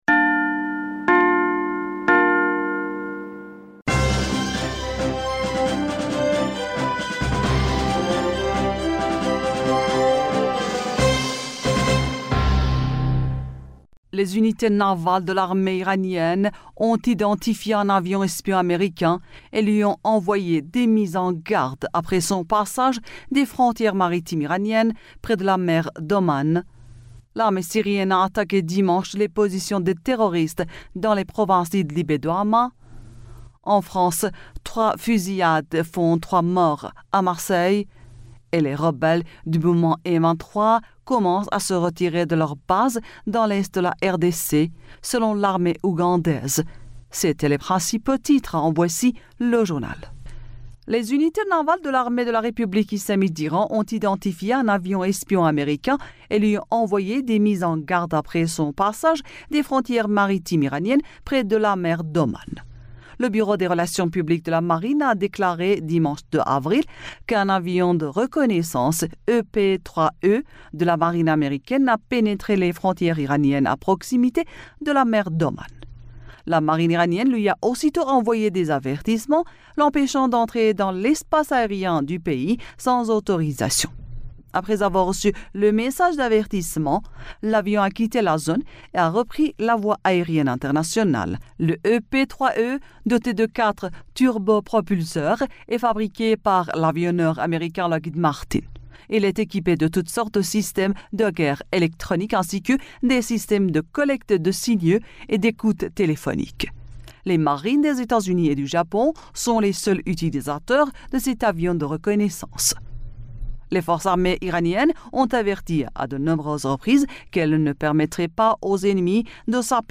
Bulletin d'information du 03 Avril 2023